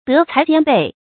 注音：ㄉㄜˊ ㄘㄞˊ ㄐㄧㄢ ㄅㄟˋ
德才兼備的讀法